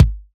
Kick Funk 6.wav